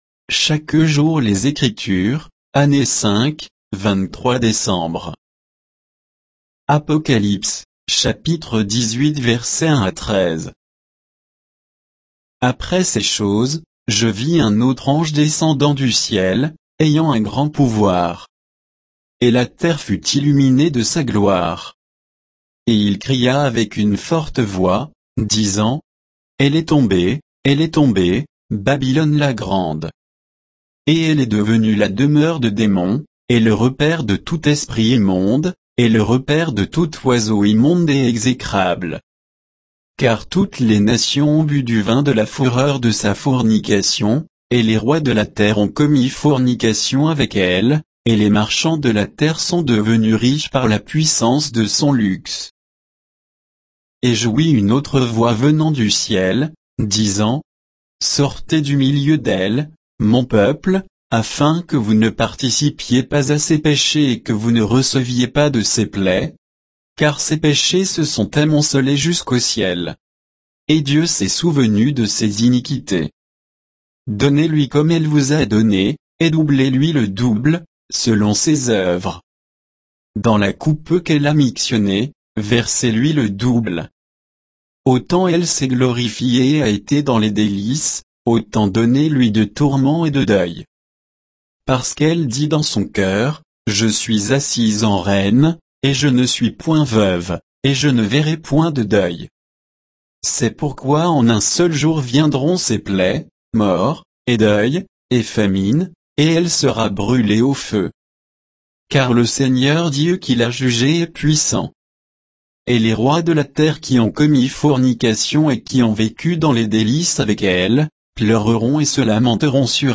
Méditation quoditienne de Chaque jour les Écritures sur Apocalypse 18